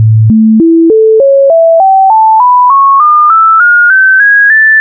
Audio abspielen werden der Grundton (\(110\;\mathrm{Hz}\)) und die ersten 16 Obertöne der Reihe nach gespielt (\(220\;\mathrm{Hz}\), \(330\;\mathrm{Hz}\), \(440\;\mathrm{Hz}\),…).
Octave-sequence.ogg